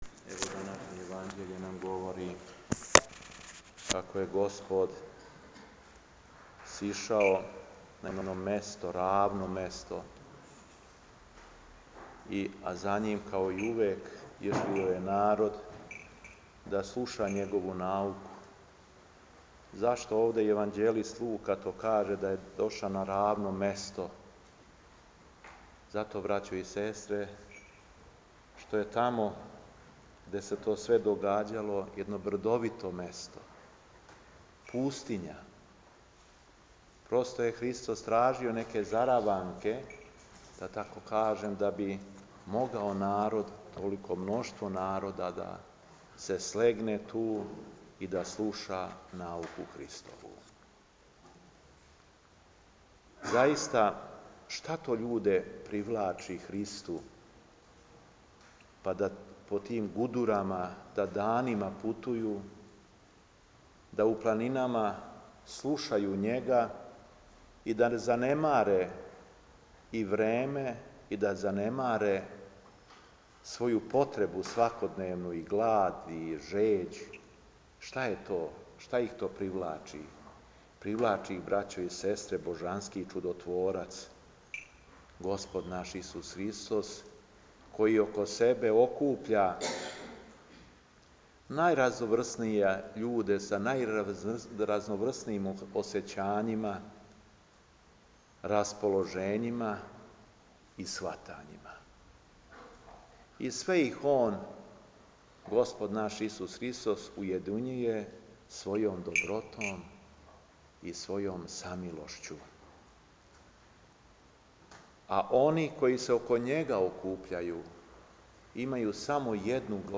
У трећи четвртак по Духовима 18. јула 2013. године, Епископ шумадијски Г. Јован служио је литургију у Старој Милошевој Цркви у Крагујевцу.
Беседа епископа шумадијског Г. Јована